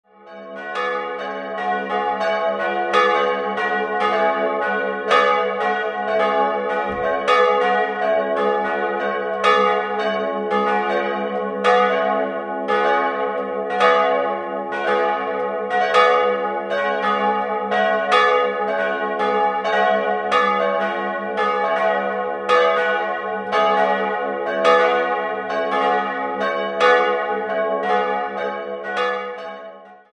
3-stimmiges Geläute: fis'-gis'-cis''
Pankratiusglocke fis'
bell
Ein Mischgeläute mit zwei Eisenhartguss- und einer Bronzeglocke, das in einem unvollständigen Motiv erklingt.